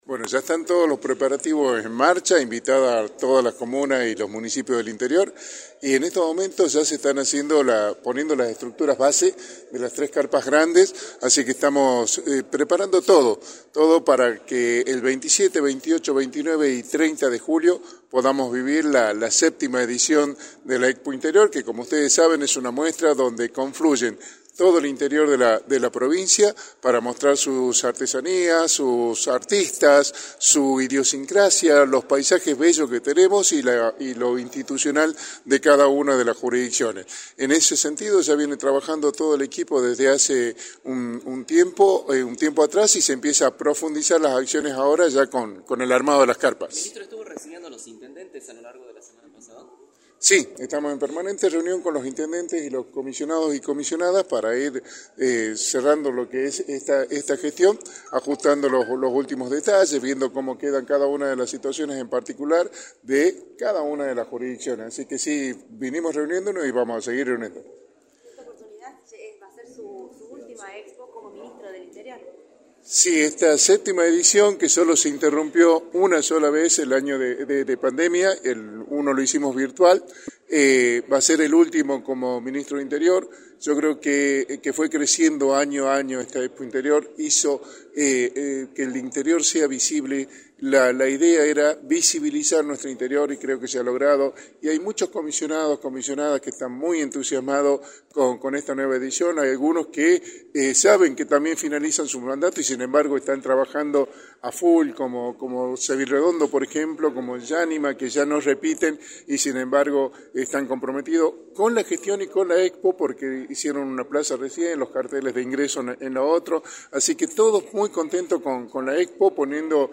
Miguel Acevedo, Ministro del Interior y Vicegobernador electo, informó en Radio del Plata Tucumán, por la 93.9, los avances en la preparación de la Séptima edición de la Expo Interior, la cual se realizará entre el 27 y el 30 de julio.
“Es una muestra donde confluye todo el interior de la provincia para mostrar sus artesanías, su idiosincrasia, los paisajes bellos que tenemos, los artistas y lo jurisdiccional de cada una de las instituciones” señaló Miguel Acevedo en entrevista para “La Mañana del Plata”, por la 93.9.